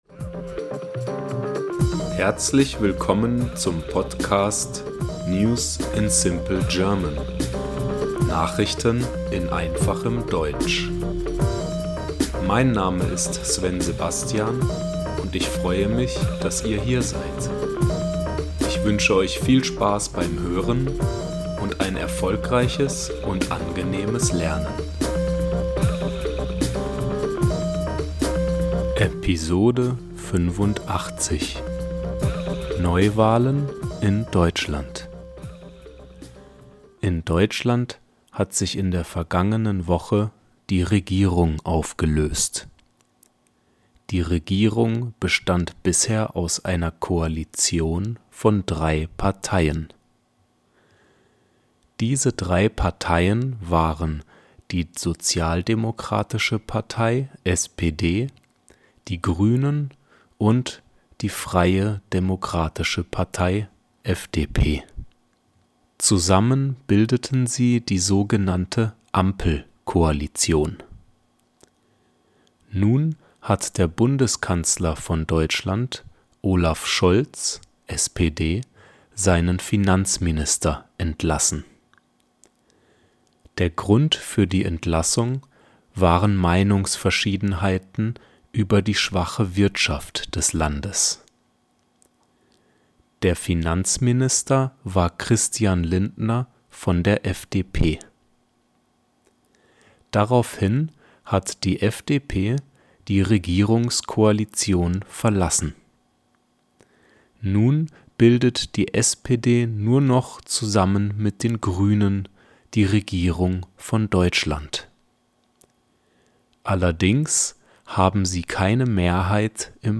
NEWS IN SIMPLE GERMAN - Nachrichten in einfachem Deutsch